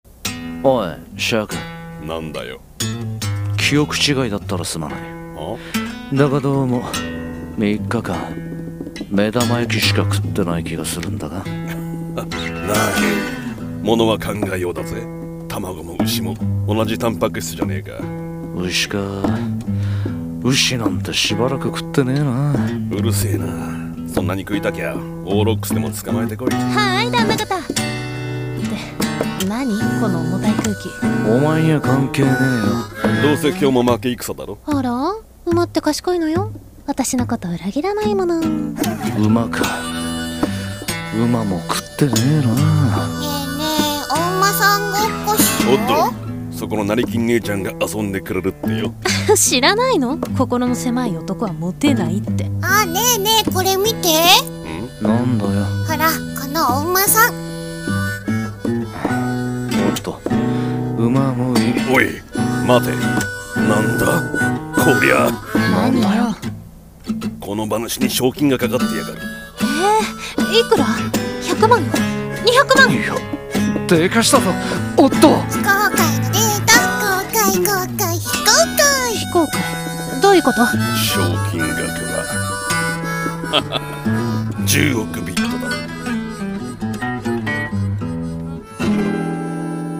【SF声劇】シークレット・ギャロップ／BOUNTY SOUL【4人台本】